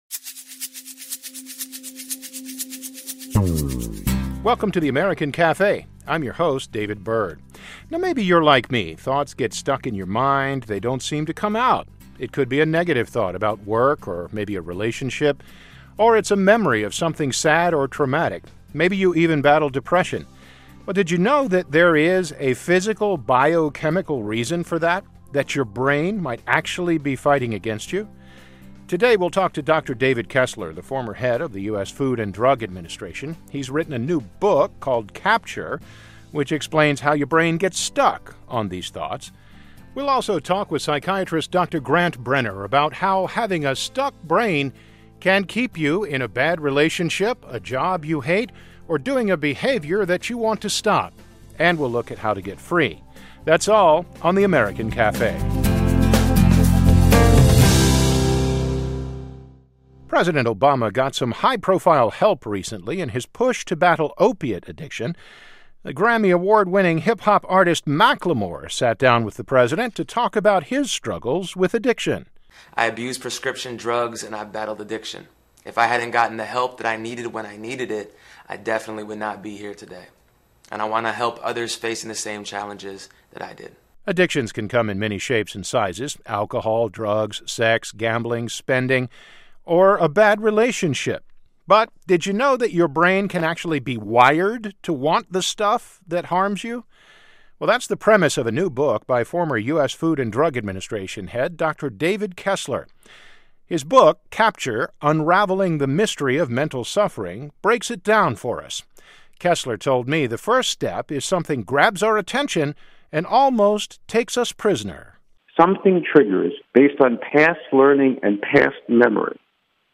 Is there a biochemical reason for that? We'll talk to two experts today about how addiction starts - and how to get free.